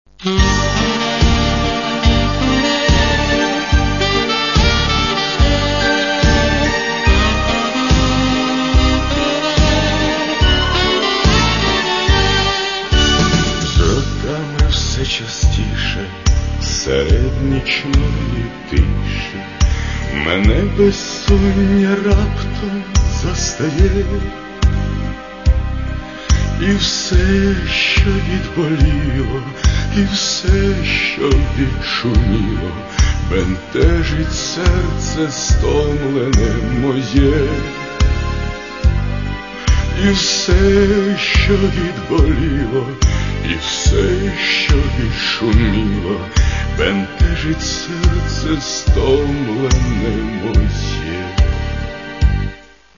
Каталог -> Эстрада -> Поэты и композиторы